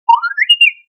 beep1.wav